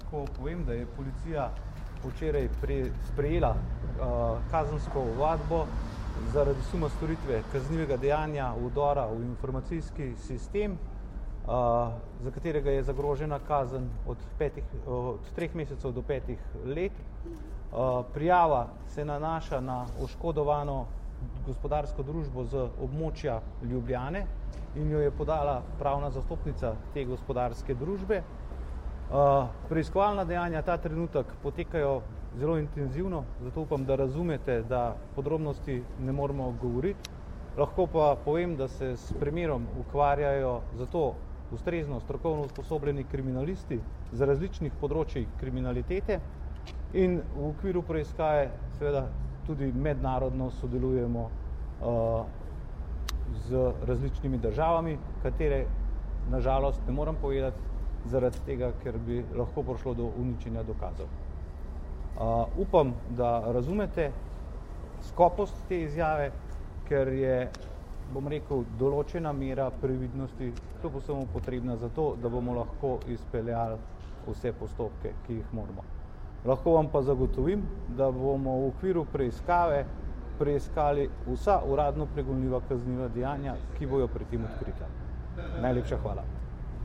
Policija je včeraj prejela prijavo in obravnava kaznivo dejanje napada na informacijski sistem družbe, ki se ukvarja s kriptovalutami. V zvezi s tem je danes, 7. decembra, pomočnik direktorja Uprave kriminalistične policije mag. Boštjan Lindav podal kratko izjavo.
Zvočni posnetek izjave mag. Boštjana Lindava (mp3)